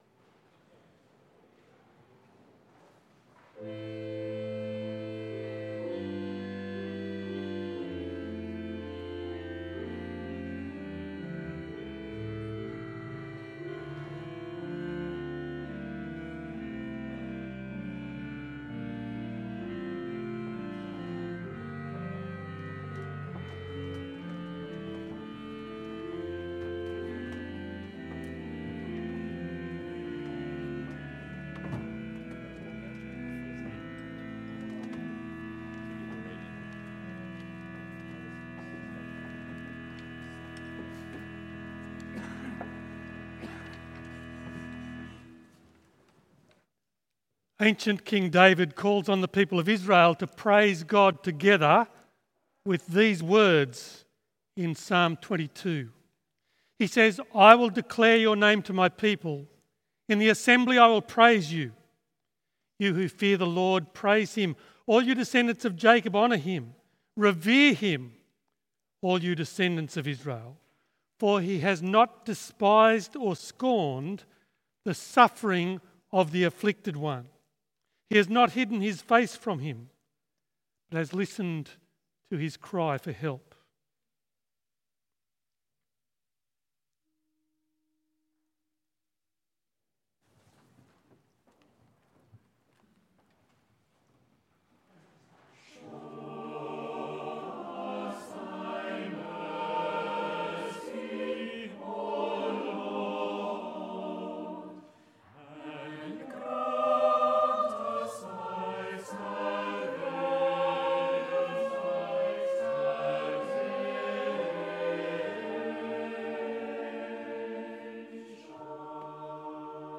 The Scots’ Church Melbourne 11am Service 2nd of April 2021
Full Service Audio